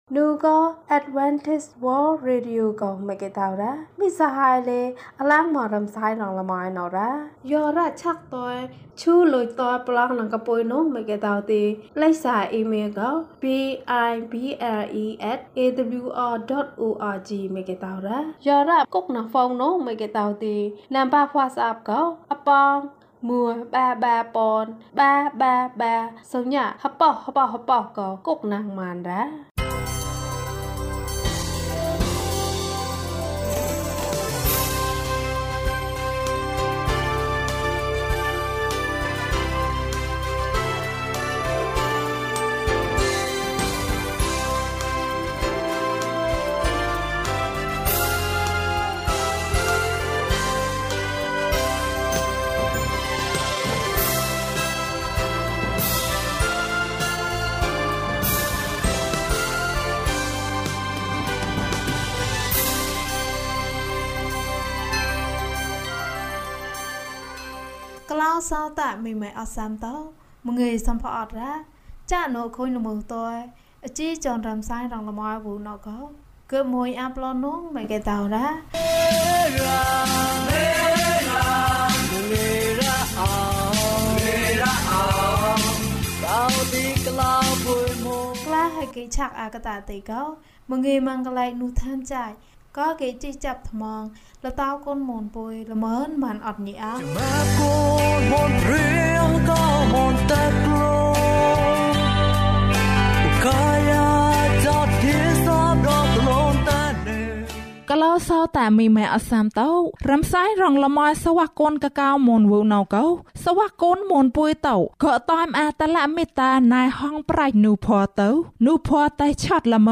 ဖန်တီးမှု။၀၂ ကျန်းမာခြင်းအကြောင်းအရာ။ ဓမ္မသီချင်း။ တရားဒေသနာ။